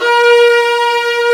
Index of /90_sSampleCDs/Roland L-CD702/VOL-1/CMB_Combos 2/CMB_Hi Strings 1
STR VIOLAS0F.wav